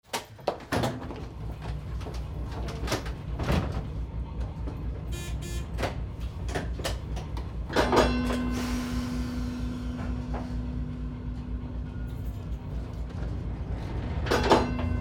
Trolley Ride Ambient 1
Transportation Sound Effects